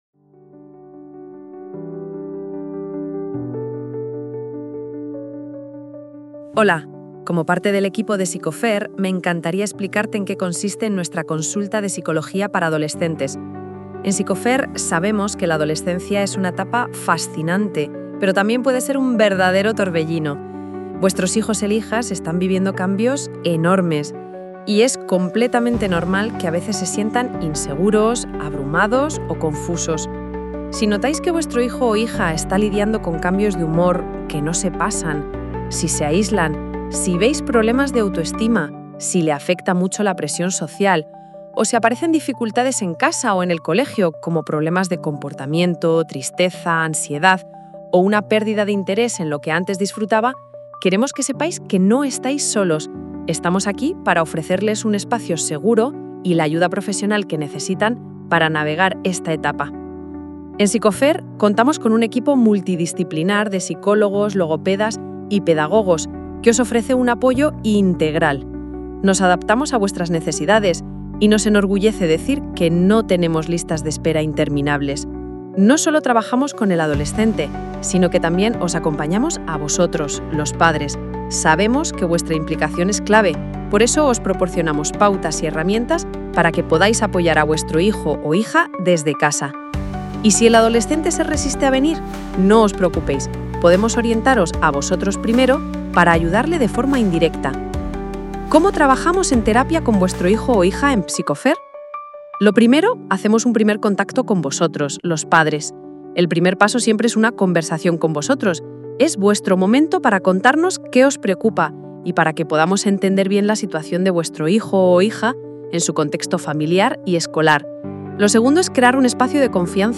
Si no te apetece leer, en este episodio de nuestro podcast te resumimos todo lo que necesitas saber sobre nuestro enfoque de terapia infantil en una conversación clara y cercana.